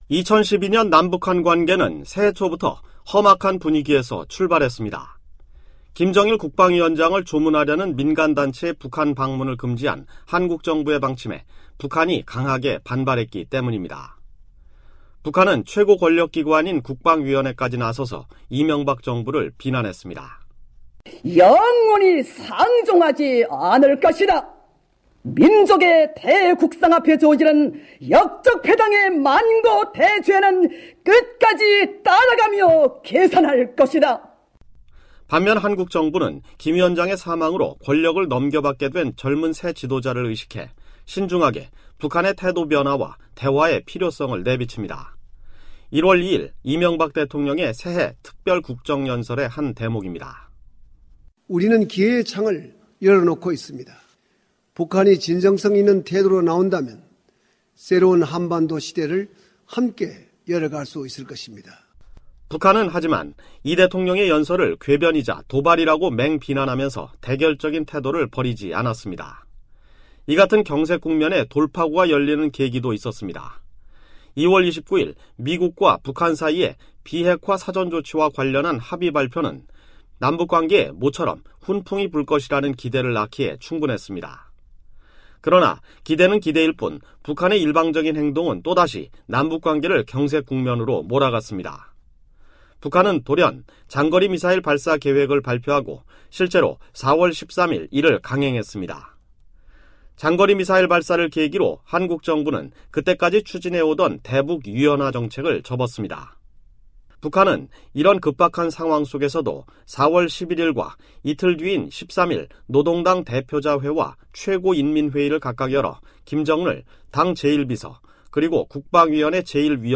2012년은 한반도가 국내외적으로 큰 변화를 겪은 한 해였습니다. 저희 VOA는 한 해를 마감하면서 북한 김정은 정권 1년과 계속되는 경제난과 미-북 관계 그리고 인권 상황 등을 살펴보는 특집방송을 준비했습니다.